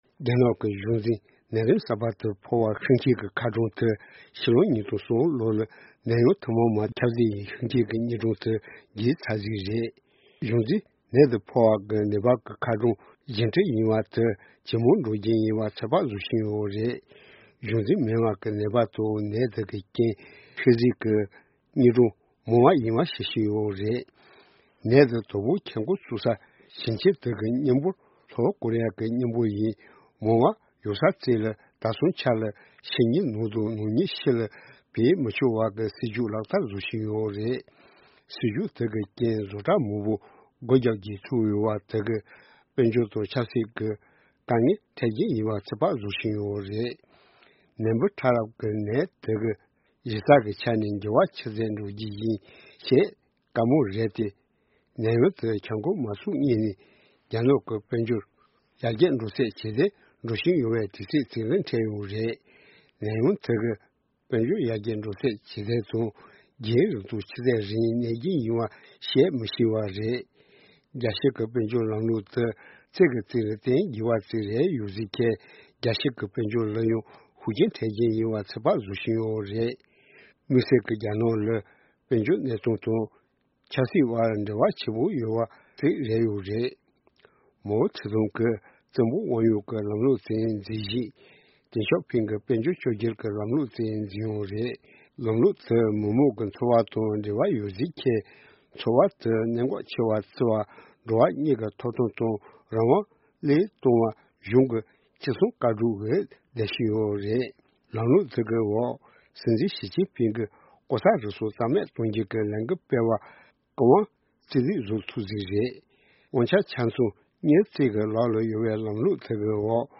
གནས་ཚུལ་ཕྱོགས་བསྒྲིགས་དང་སྙན་སྒྲོན་ཞུ་རྒྱུ་རེད།